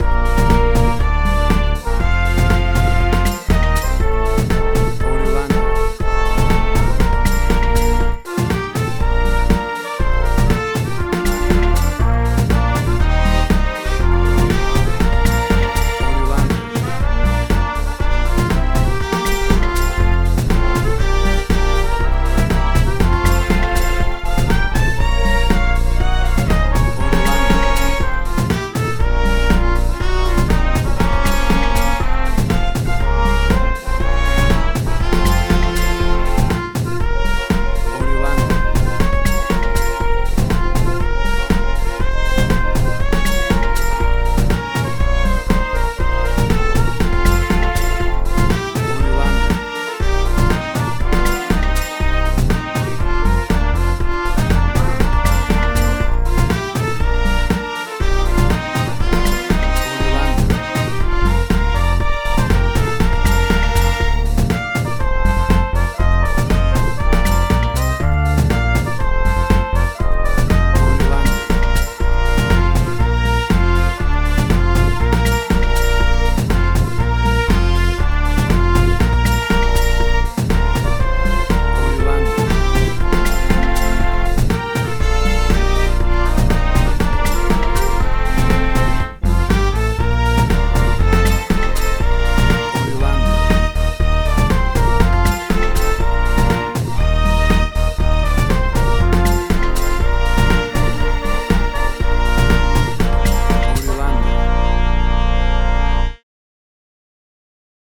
A pumping energetic dance remix
WAV Sample Rate: 32-Bit stereo, 44.1 kHz